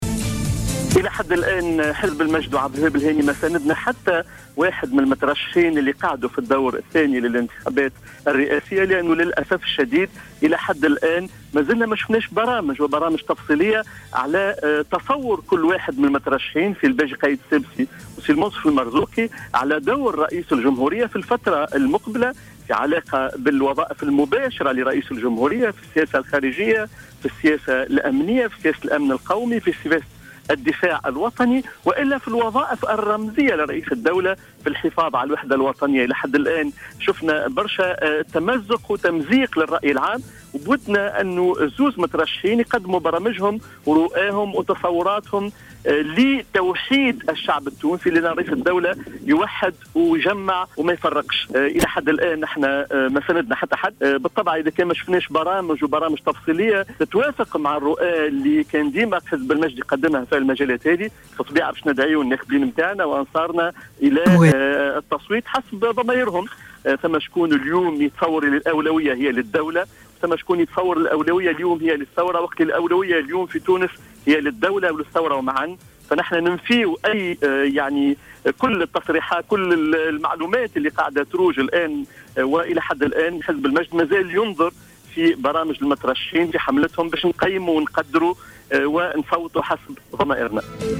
نفى رئيس حزب المجد،عبد الوهاب الهاني اليوم في تصريح ل"جوهرة أف ام" دعم حزب المجد للمرشّح للانتخابات الرئاسية الباجي قائد السبسي.